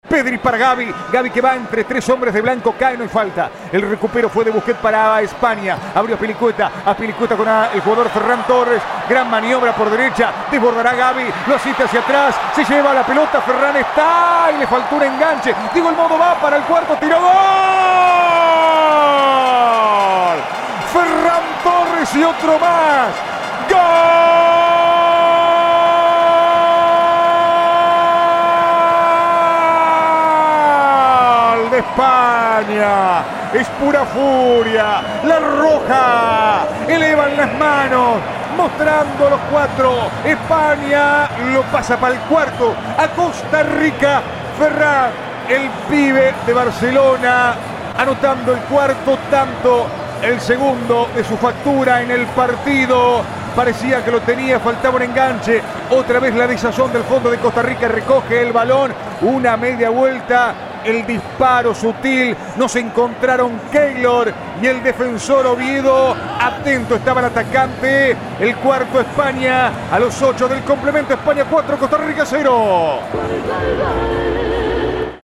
Audio. 4º gol de España vs Costa Rica - Ferran Torres (relato